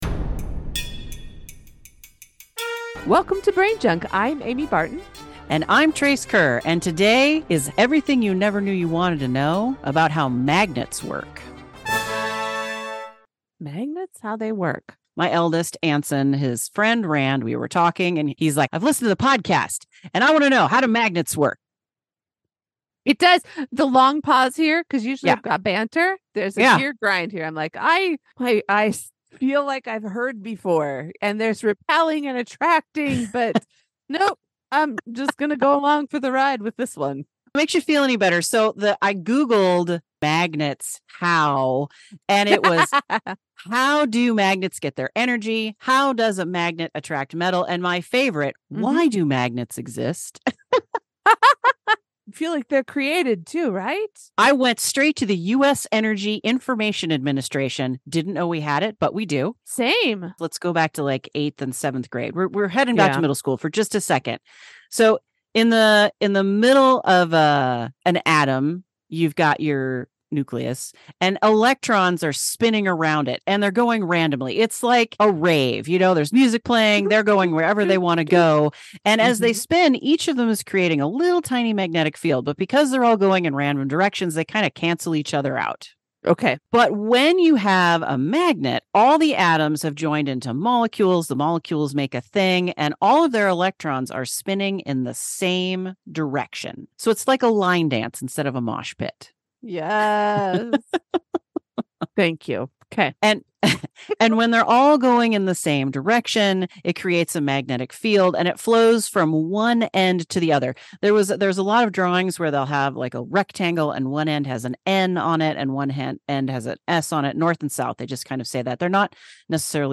First off, we recorded on Zoom ( I KNOW it's ). Sorry about the sound quality.